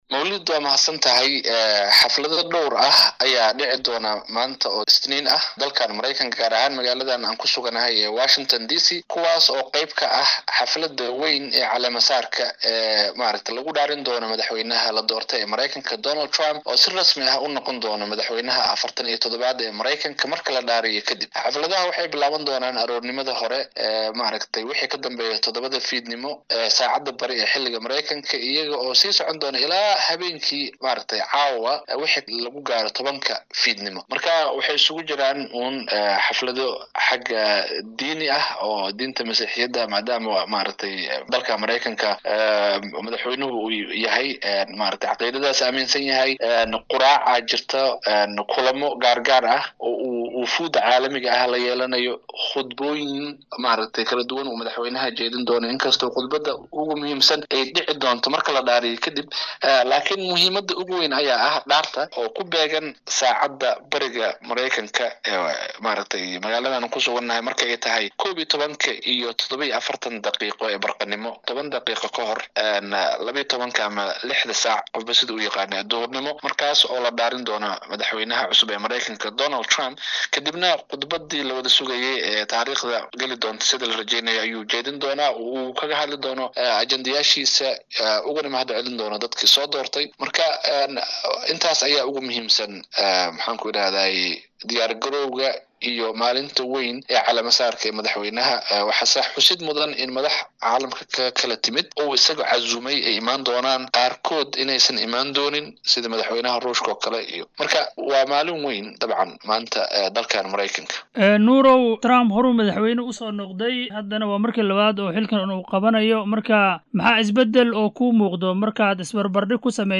DHAGEYSO:WAREYSI GAAR AH: Madaxweynaha la doortay ee Maraykanka Donald Trump oo maanta la caleema-saaraya